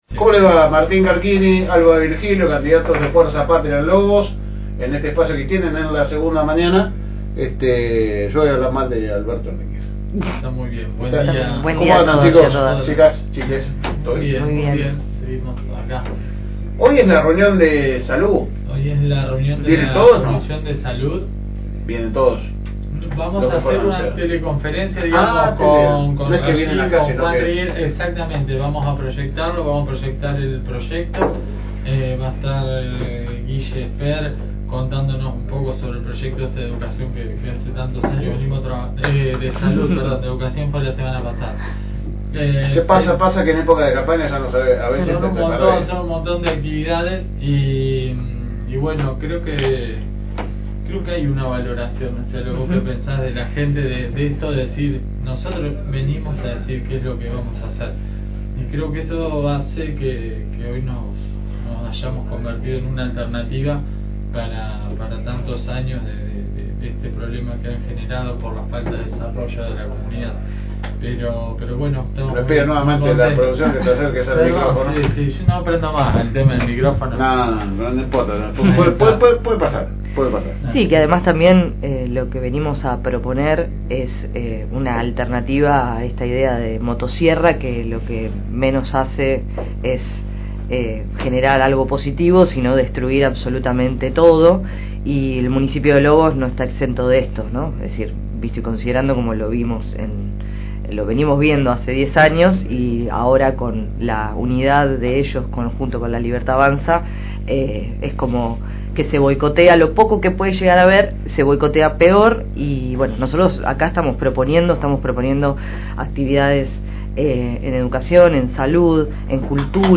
por los estudios de la Fm Reencuentro 102.9.